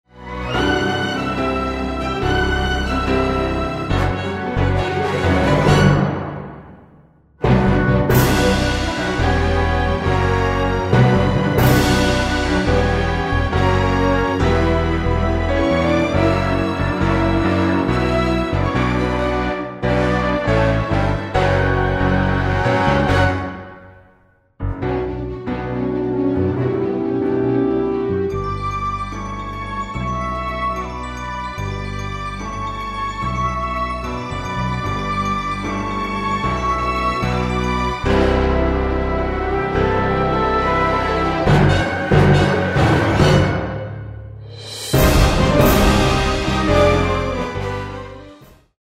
This is a professional backing track of the song
Karaoke , Instrumental
orchestral